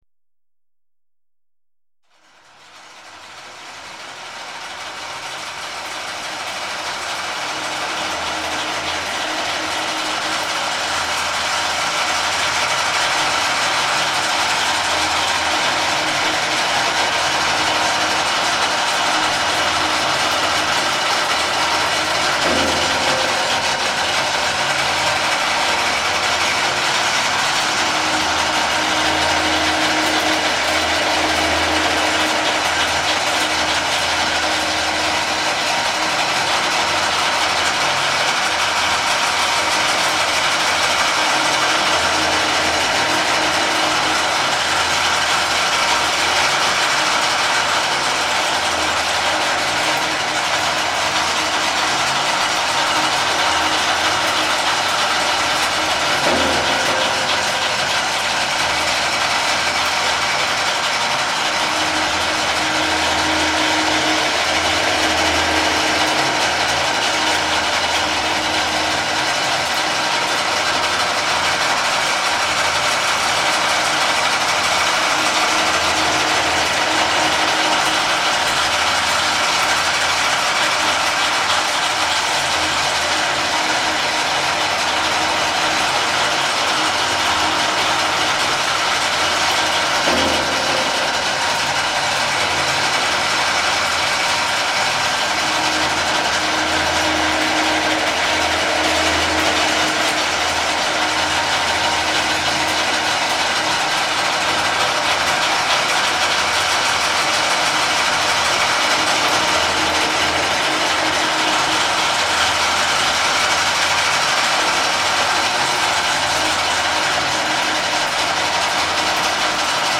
Generate clicky noises in your browser.
Add layers of noise by playing the loops to your right.